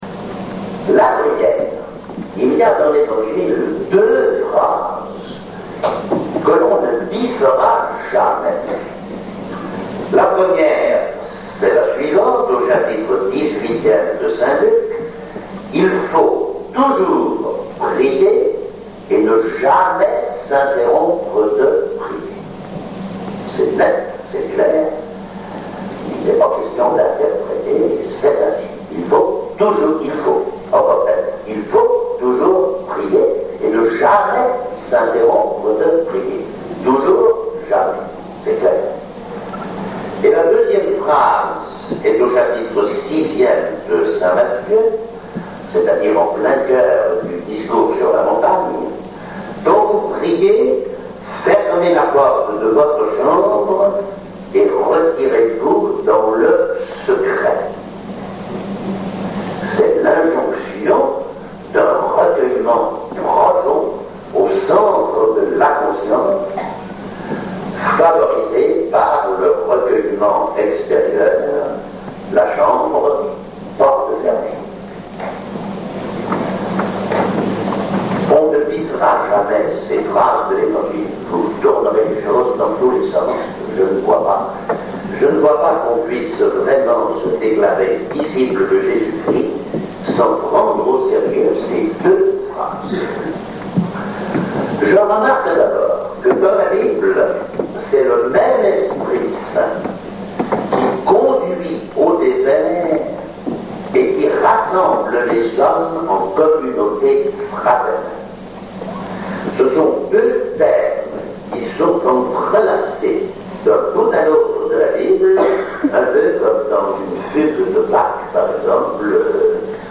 Extraits d’une conférence